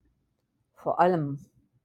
vor allem (vor ALL-em)